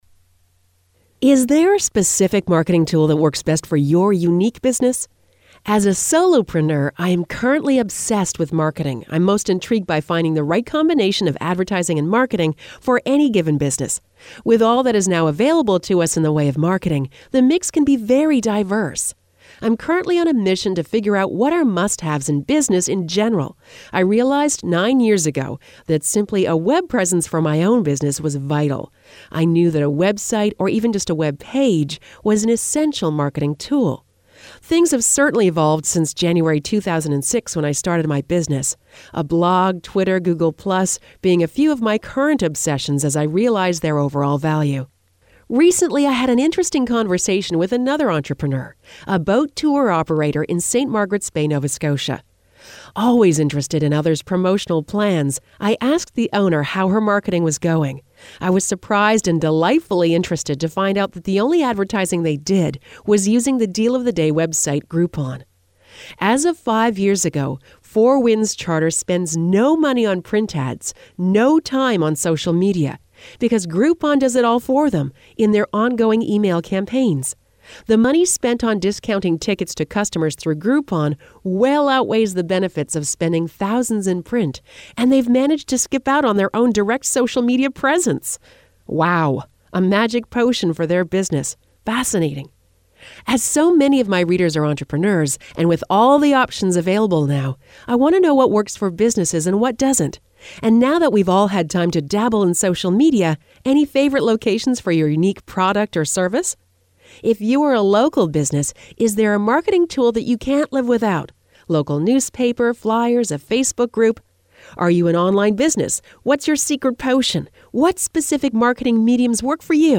audio-blog-do-you-want-to-know-a-secret.mp3